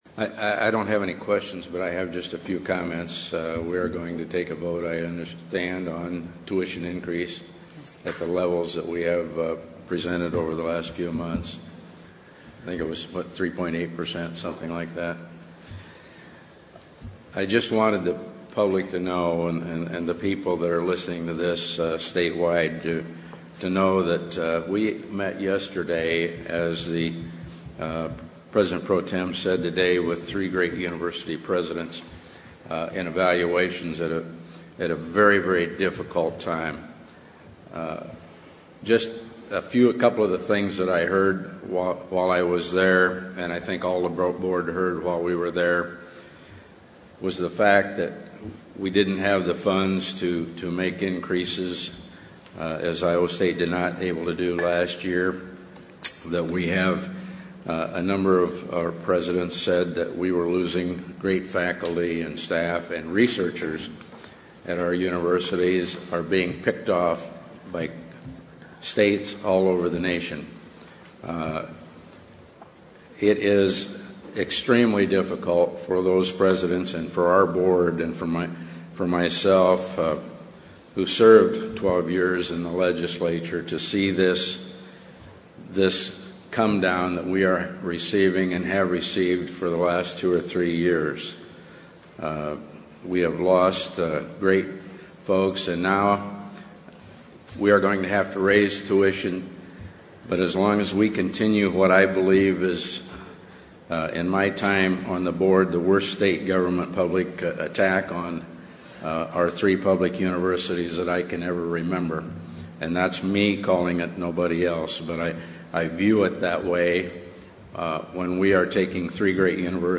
AUDIO of McKibben’s remarks, 7:00